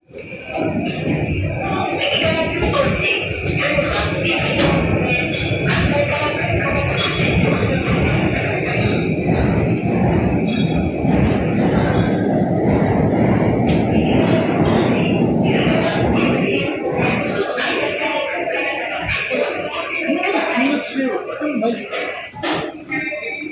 蚊の羽音のようなキーンとする音であることからこの名で呼ばれており、一般的には若年層には聞こえるが中高年には聞こえないと言われている。
念のためスマホのレコーダー機能で音を録音しておいた。マイク等を持っていないので、録音状態はそれほど良いとは言えないけど、記録しておこう。
銀座線ホームでは17kHzの音がうっすら出ており、真横に薄い帯を形成している。周囲の音に比べればごく小さく、意識しないと聞き取れないレベルであることがわかる。
▼銀座線渋谷駅ホームの音